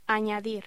Locución: Añadir